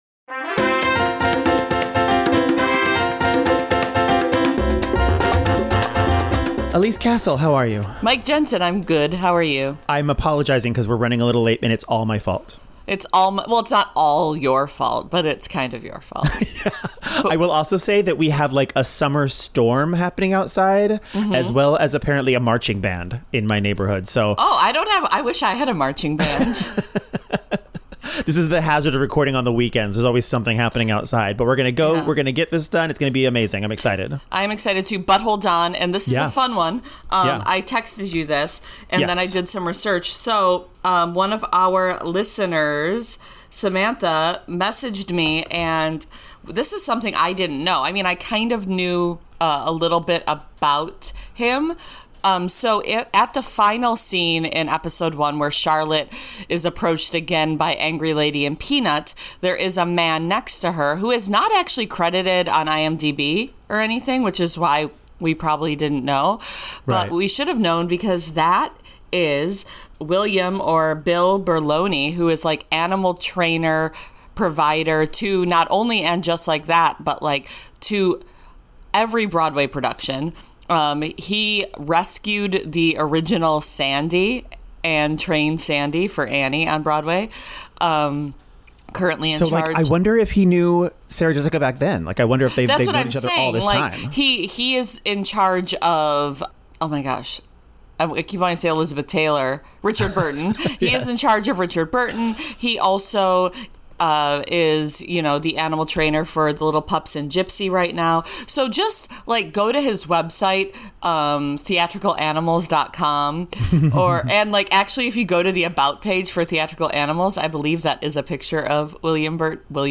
I will also say that we have like a summer storm happening outside as well as apparently a marching band in my neighborhood.